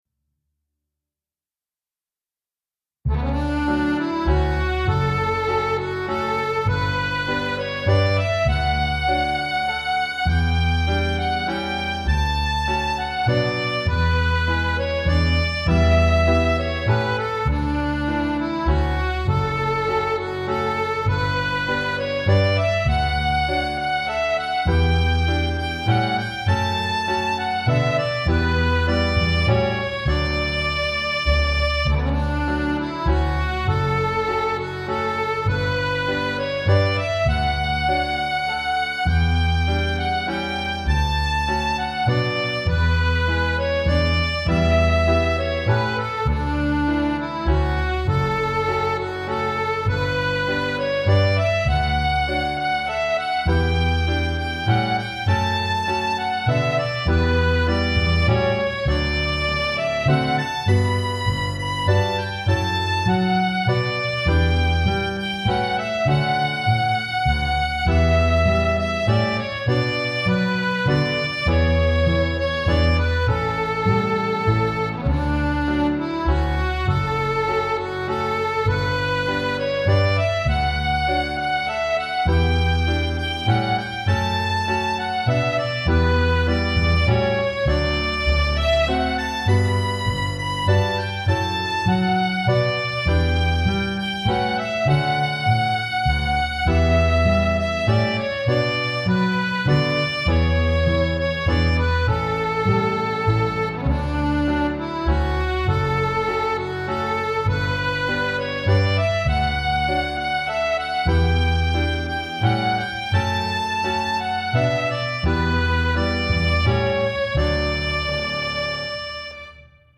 Violin
A collection of original tunes in the traditional style for
fiddle and accordian.